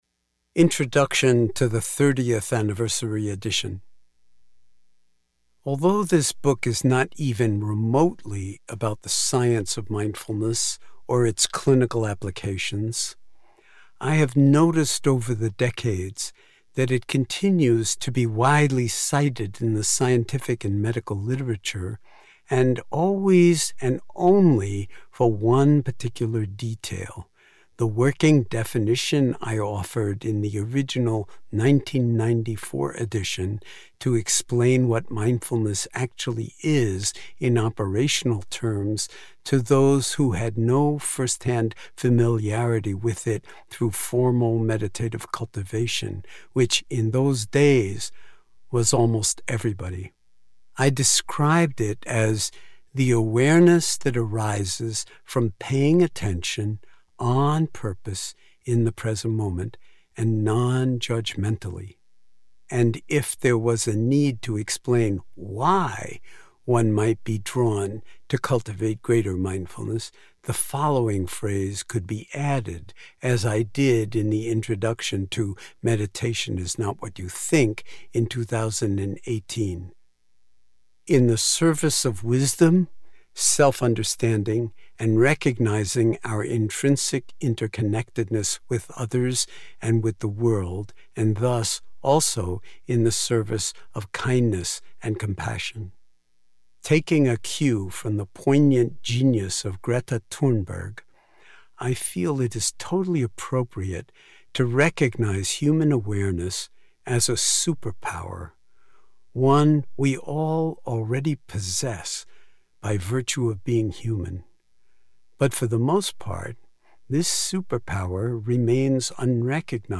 We provided Mr. Kabat-Zinn’s recording for Hachette Publishing.